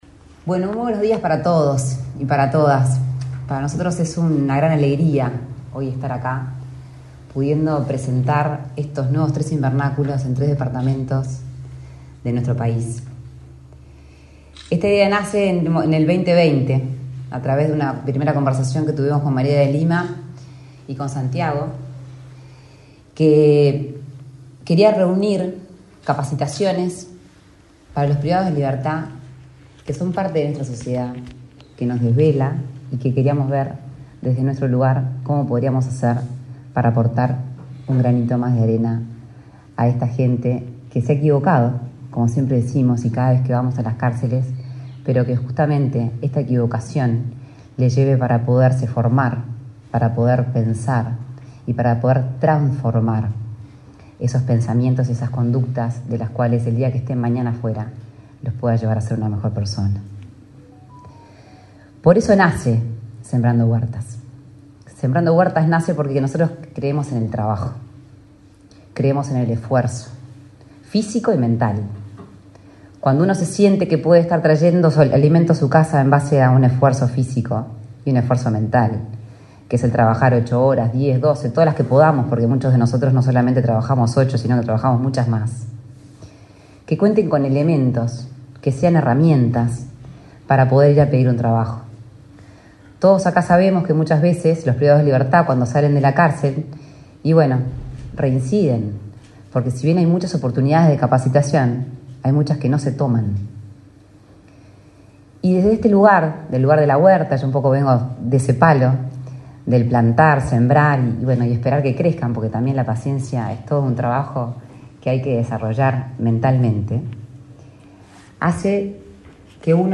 Conferencia de prensa por inauguración de invernáculo del programa Sembrando en INR de San José